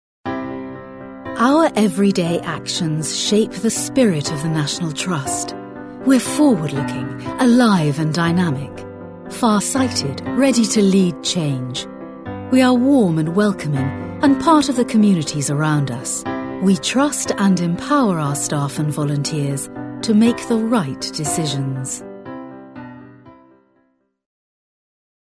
English british female voice over artist. sophisticated, warm, friendly , business voice
britisch
Sprechprobe: Industrie (Muttersprache):
I have a clear friendly warm british voice.